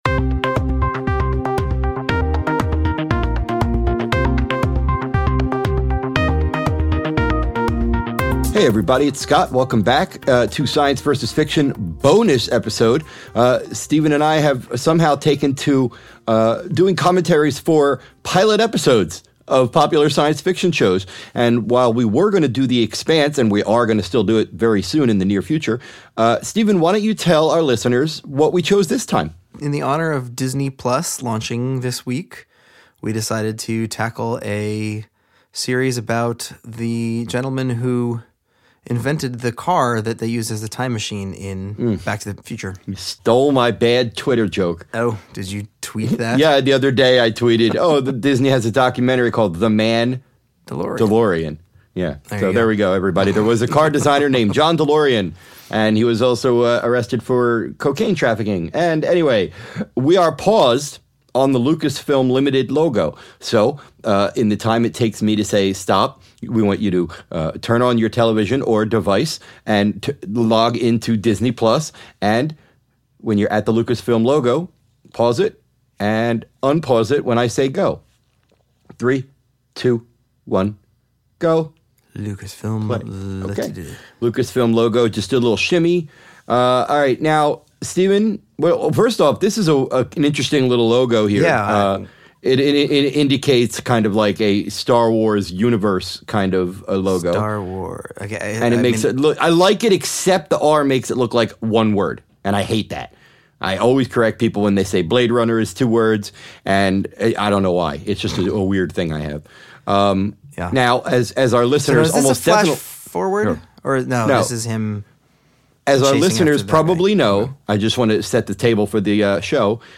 As with all of our commentary tracks, you can watch along with the episode yourself, or you can listen to it on its own.